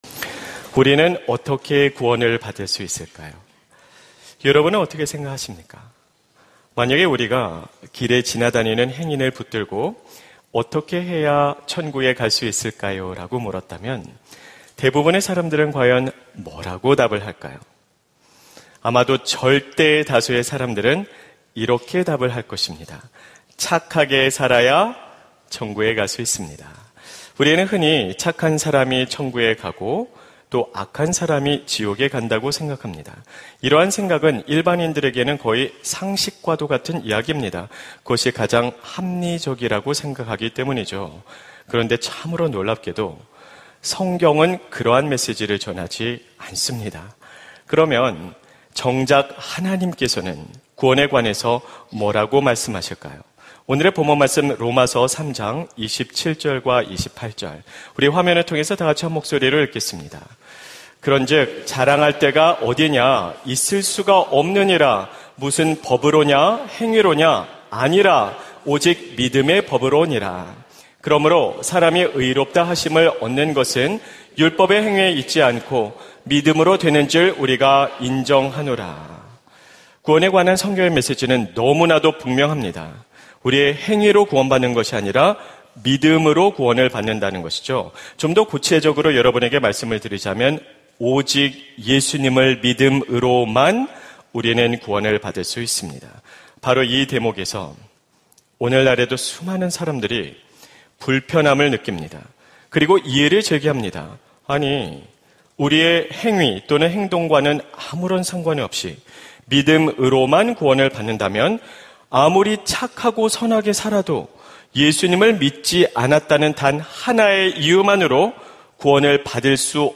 설교 : 주일예배 착하게 살았는데 왜 구원받지 못하나요?